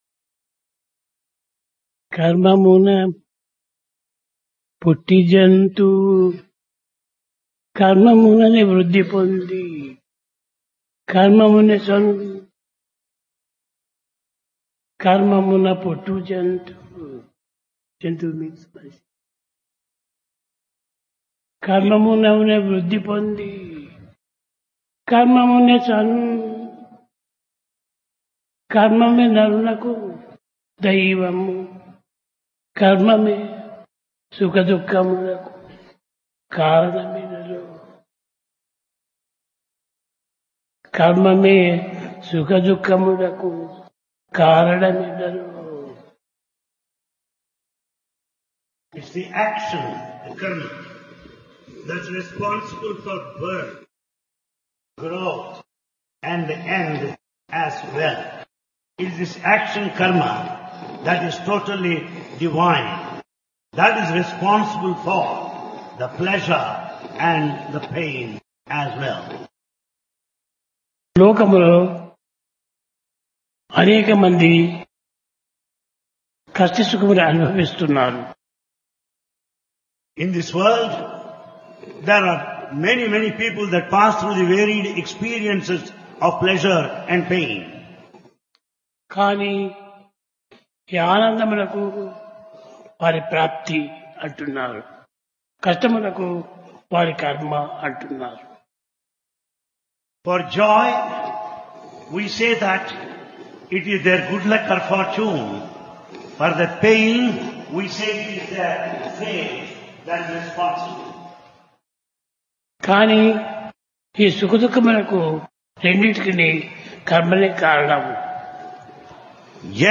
Divine Discourse
Place Prasanthi Nilayam Occasion Dasara, Vijayadasami